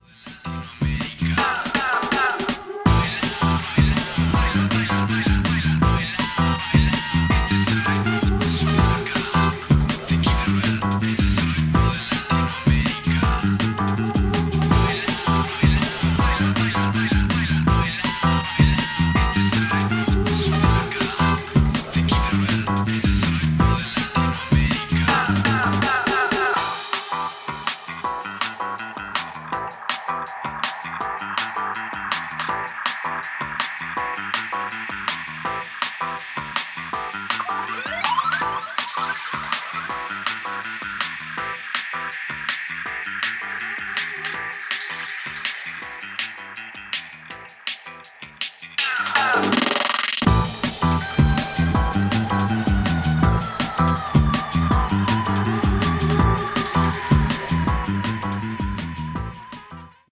当社にて、BGM用に製作している楽曲のいくつかを、サンプルとしてご紹介致します。
各曲、ステレオと 5.1ch Surround の二つのフォーマットをご用意致しました（楽曲の内容は同一です）。